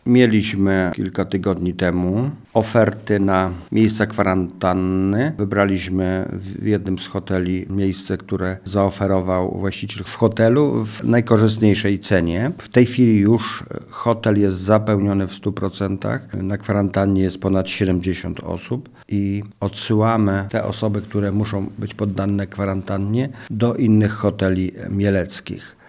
Dziś już wiadomo, że z pomocy tej skorzystało ponad 70 osób co oznacza, że w hotelu jest brak miejsc dla kolejnych osób chcących taka kwarantannę przeprowadzić. Mówi starosta mielecki Stanisław Lonczak.